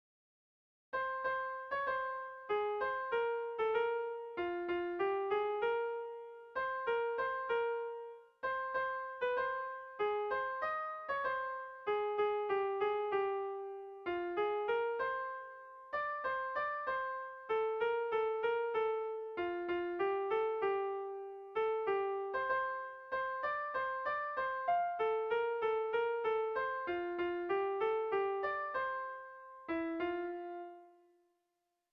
Erlijiozkoa
Zortziko handia (hg) / Lau puntuko handia (ip)